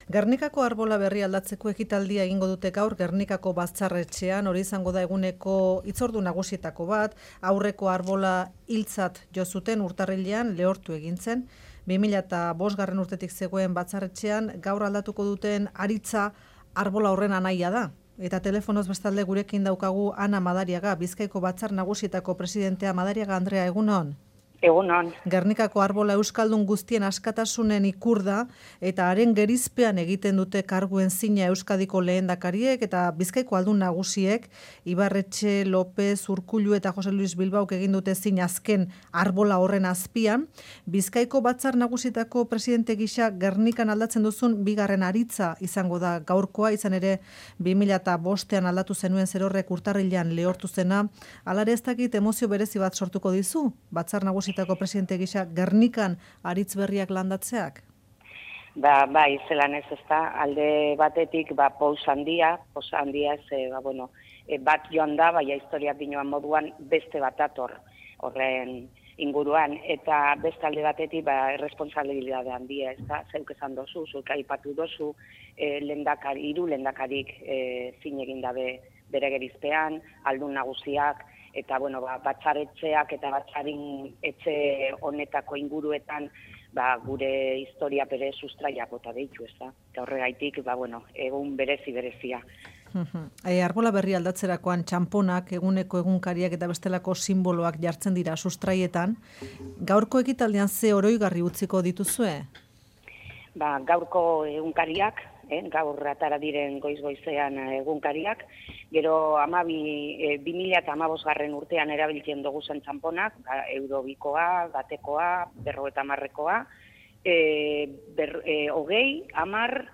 Gernikako arbolaren aldaketa, Faktoria, Euskadi Irratian. Ana Madariaga, Bizkaiko Batzar Nagusietako presidentea elkarrizketatu dugu.